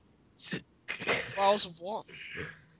Laugh (atleast i think it is)